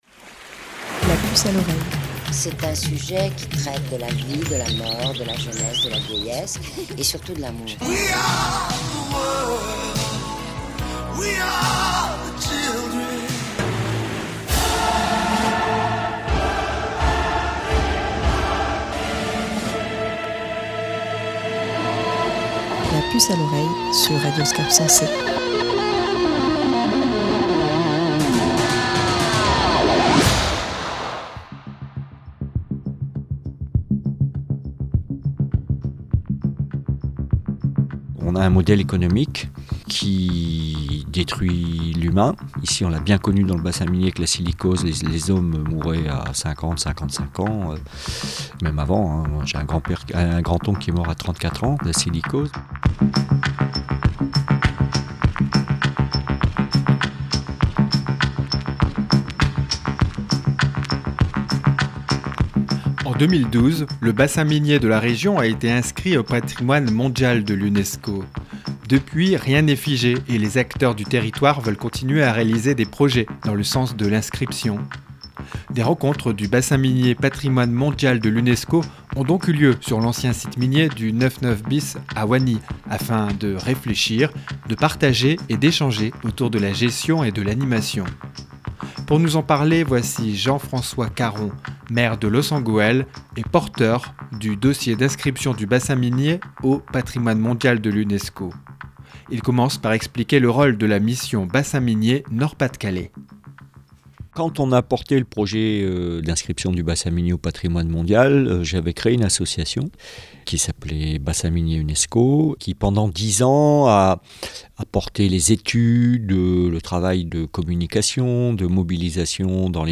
Explications de Jean-Francois Caron, maire de Loos en Gohelle.